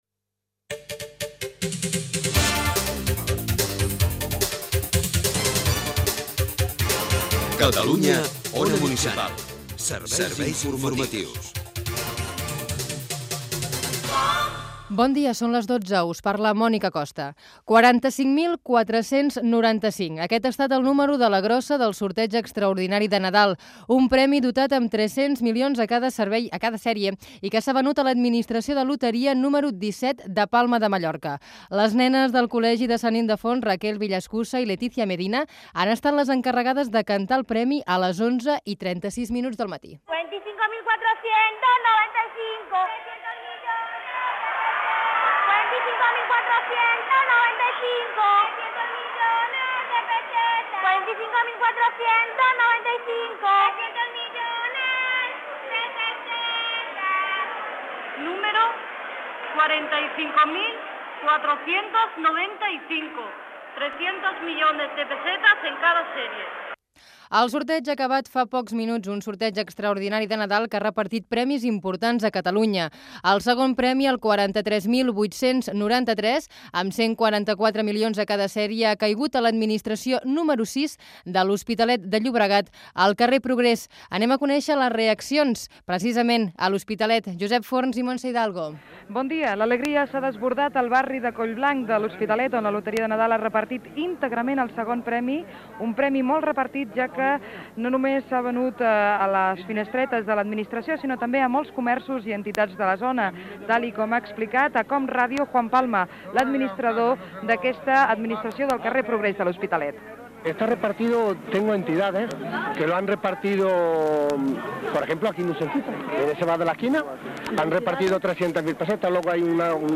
Identificació com Catalunya Ona Municipal. Careta,informació sobre la rifa de Nadal. Connexions amb L'Hospitalet de Llobregat, el Poble Nou i Sabadell Gènere radiofònic Informatiu